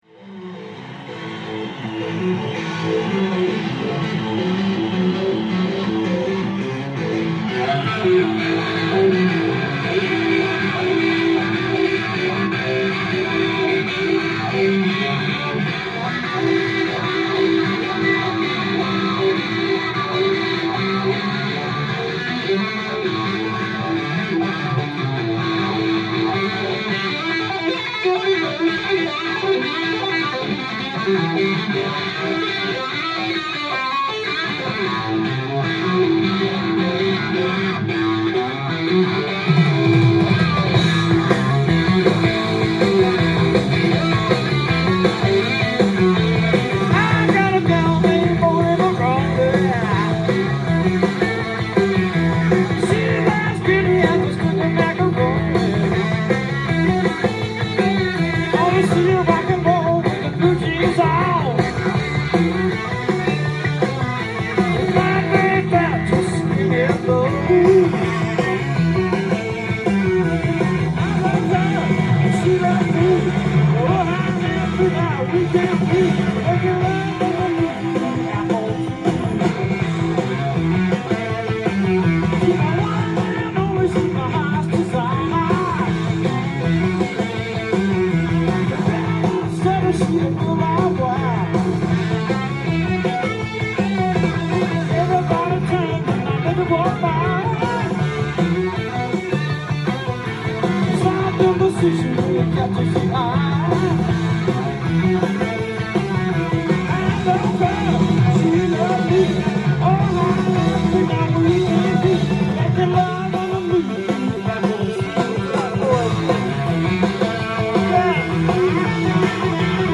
ジャンル：ROCK & POPS
店頭で録音した音源の為、多少の外部音や音質の悪さはございますが、サンプルとしてご視聴ください。